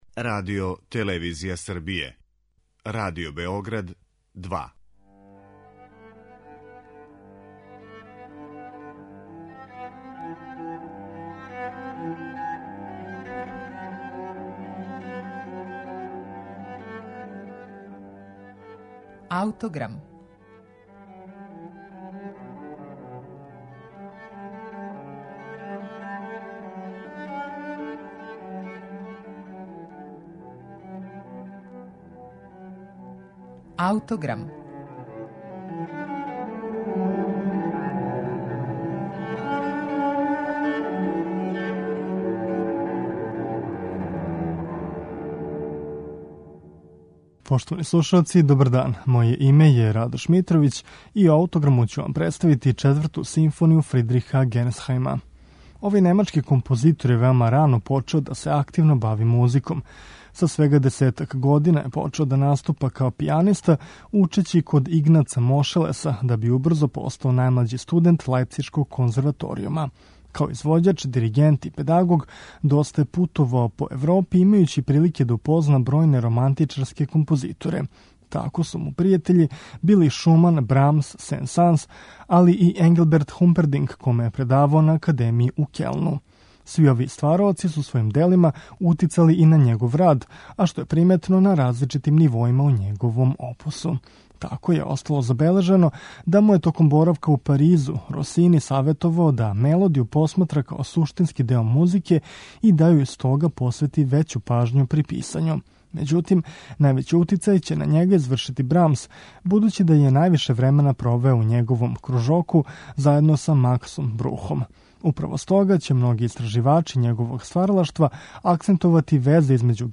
У њима је приметна изузетна оркестраторска вештина и, романтичарски израз, уз вођење рачуна о класицистичкој симетрији и форми.